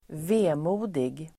Ladda ner uttalet
vemodig adjektiv, melancholic Uttal: [²v'e:mo:dig] Böjningar: vemodigt, vemodiga Synonymer: dyster, melankolisk, nedstämd, sorgsen, sorgsenhet Definition: (lätt) sorgsen (sad) Exempel: en vemodig melodi